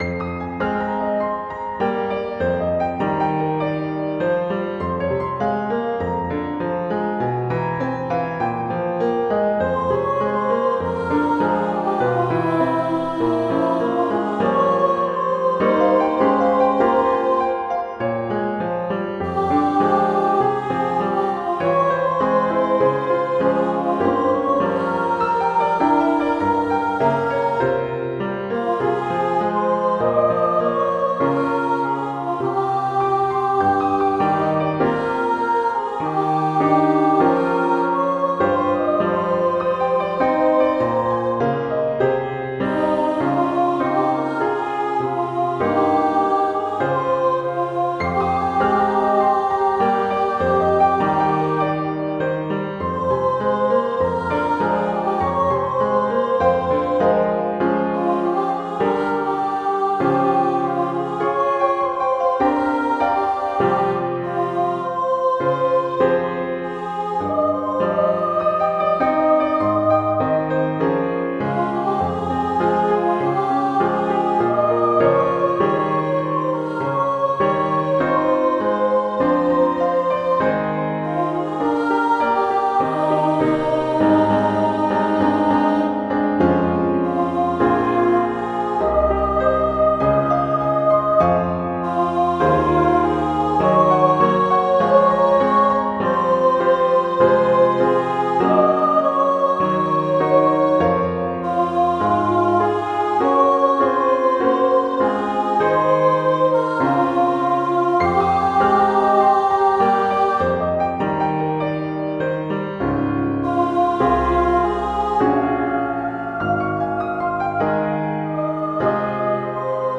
for solo voice and piano